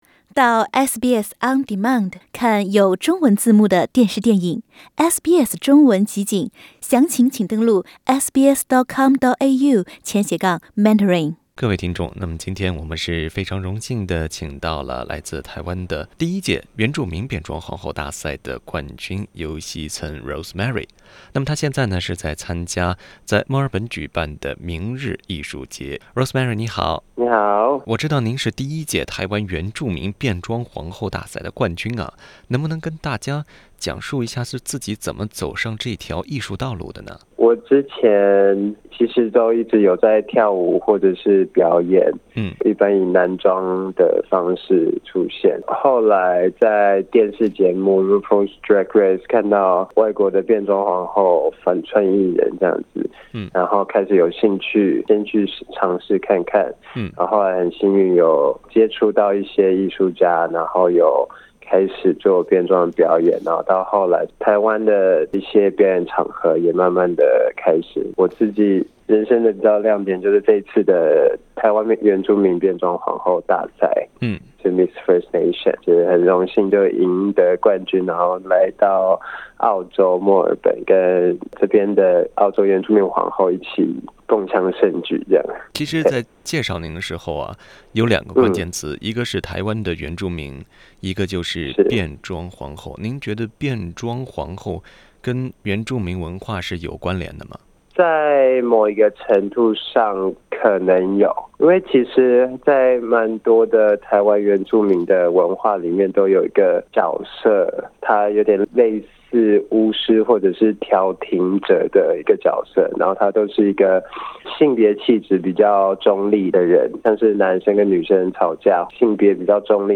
【专访】我是原住民，我也是“变装皇后”